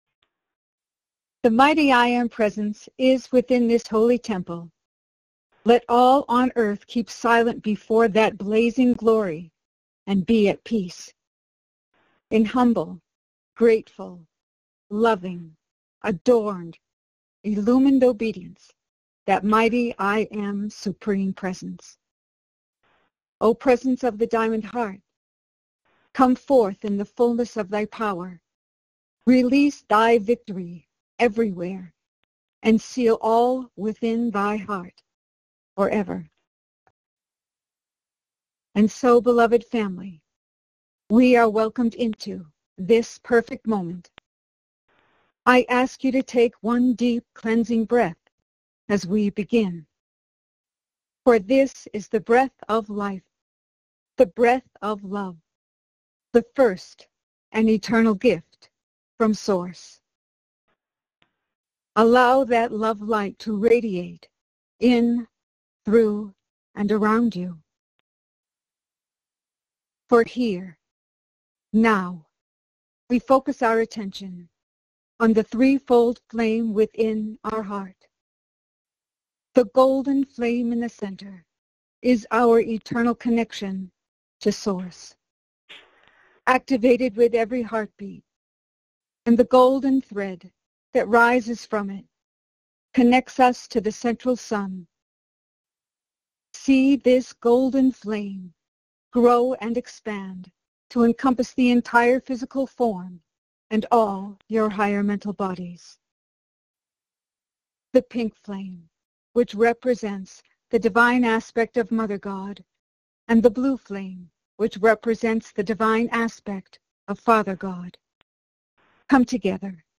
Follow along in group meditation with Archangel Michael.